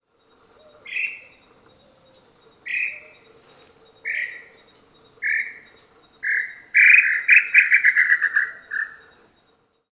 Inhambu-chintã (Crypturellus tataupa)
Nome em Inglês: Tataupa Tinamou
Localidade ou área protegida: Reserva Privada y Ecolodge Surucuá
Condição: Selvagem
Certeza: Gravado Vocal